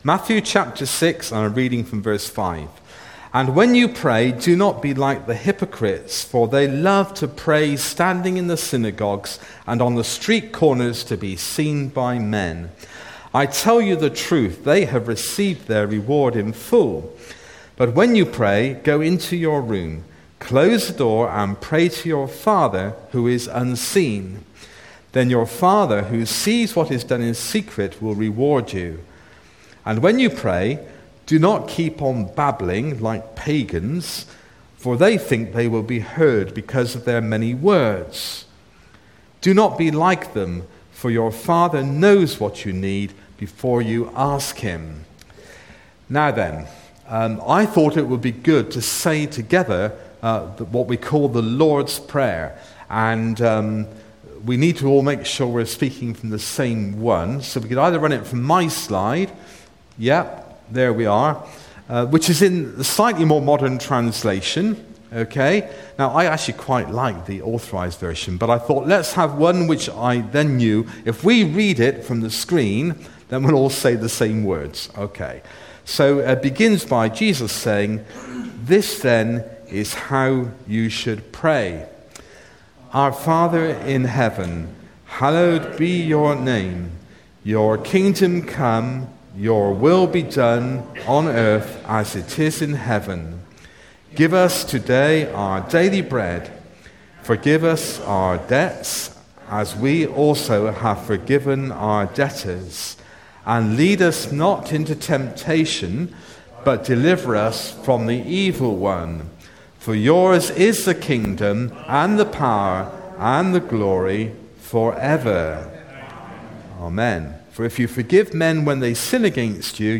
The Rough Guide to EVANGELISM – sermon